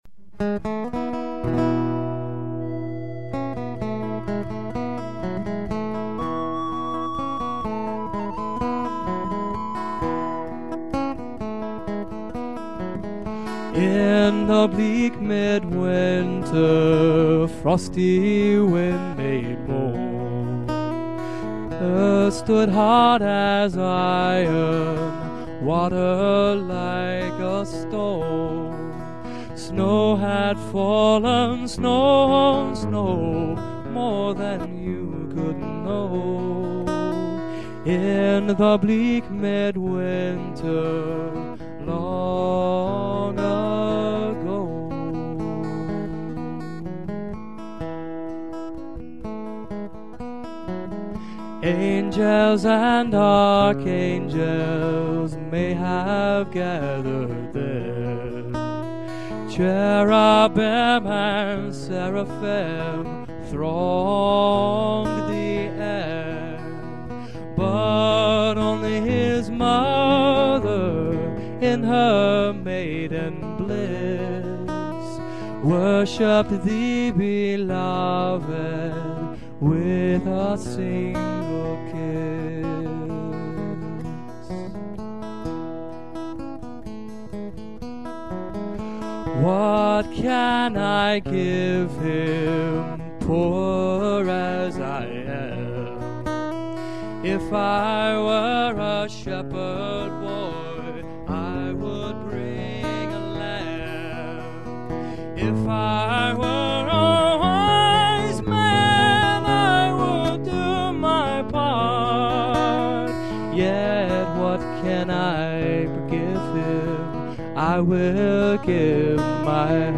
Recorded on December 4, 2002 (the day it snowed) for Webb TV-19's Christmas Special.